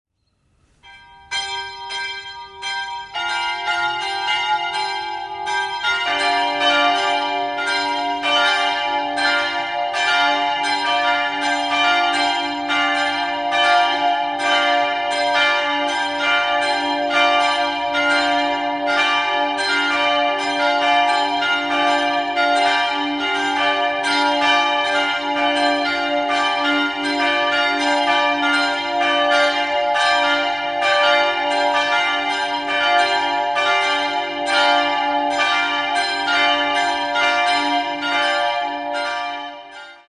3-stimmiges TeDeum-Geläute: d''-f''-g''
Betglocke
d''+2
Friedensglocke
f''+4
Dankglocke
g''+4
Alle Glocken sind in mittelschwerer Rippe gegossen.